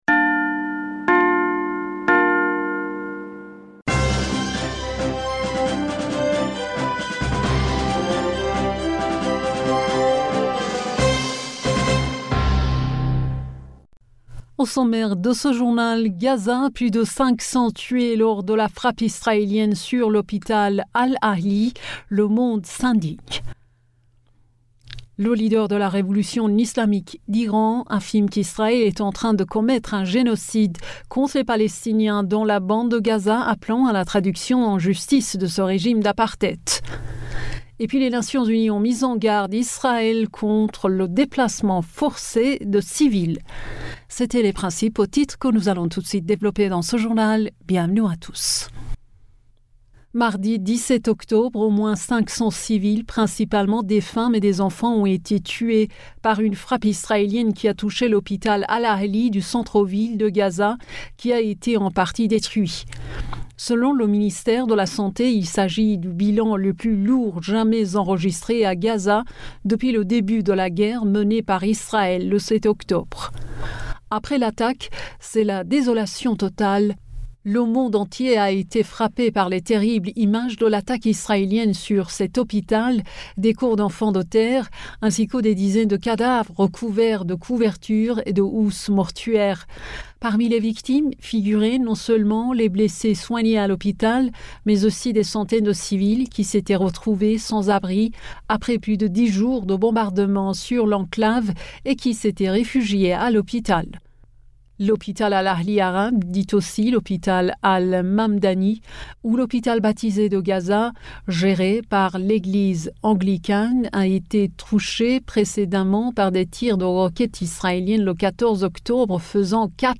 Bulletin d'information du 18 Octobre 2023